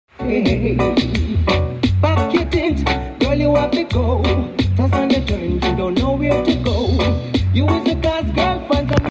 River Rafting Sound Effects Free Download